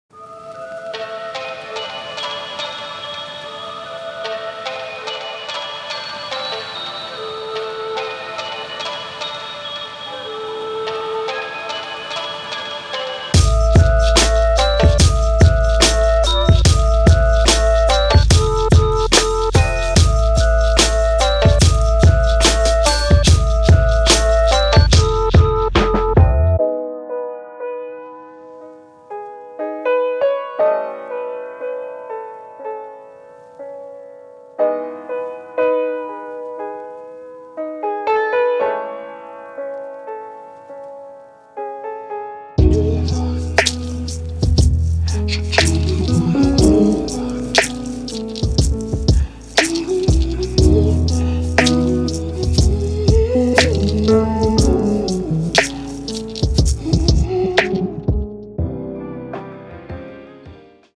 Lo-Fi
Made for those hazy, late nights